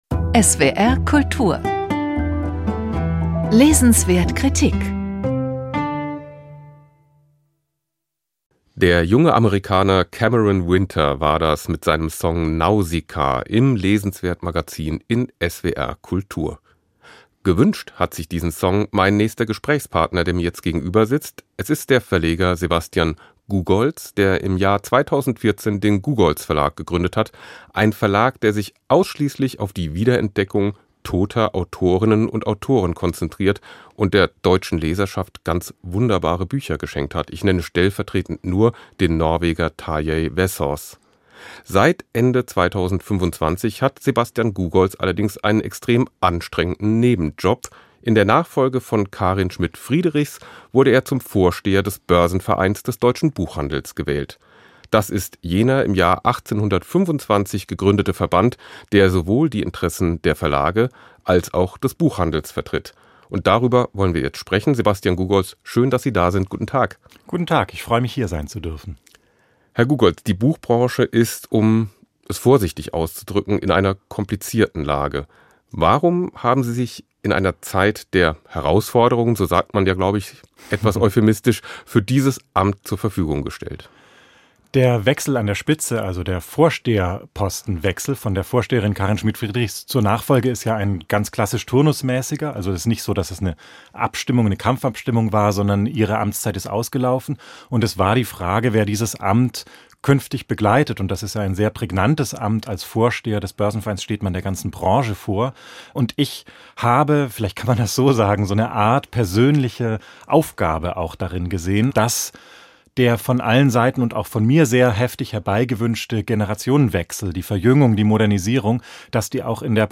Im Gespräch gibt er Auskunft über seine Pläne.